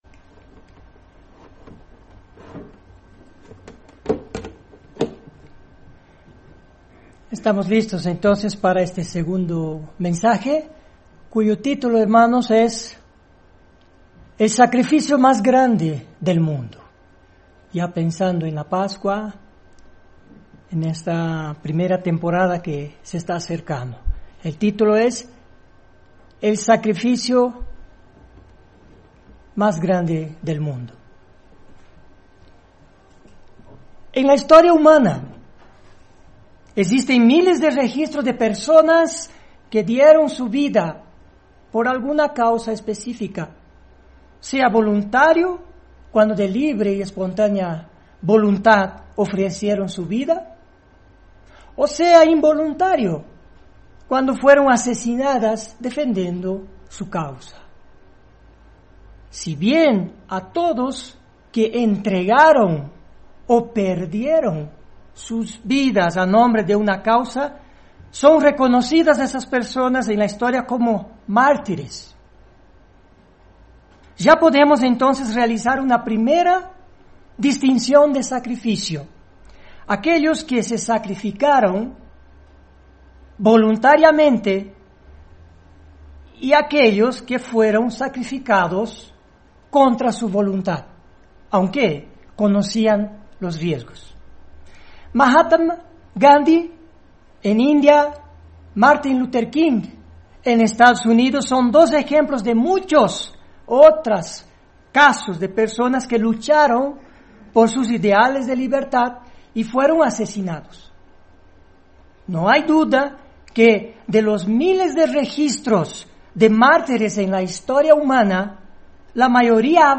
Miles de personas han dado sus vidas por una causa; algunas voluntariamente, otras aceptando las consecuencias de sus creencias. Mensaje entregado el 30 de marzo de 2019.